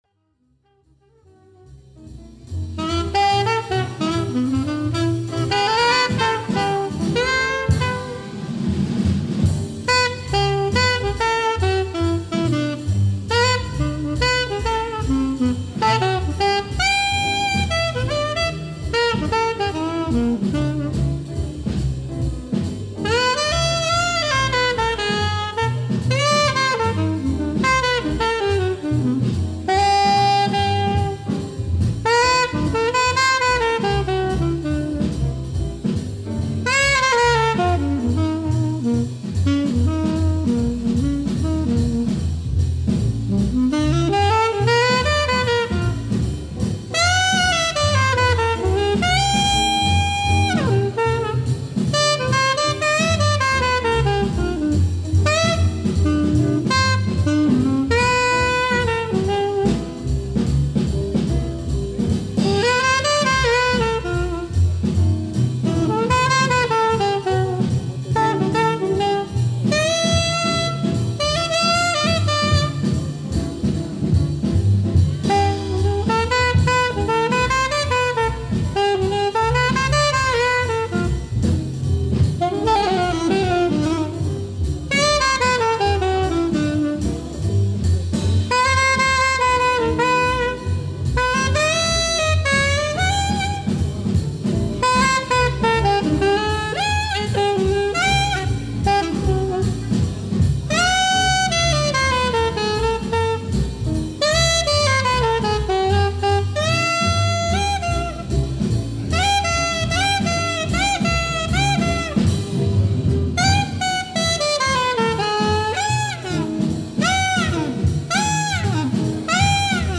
The master then wraps it up with a blues cry.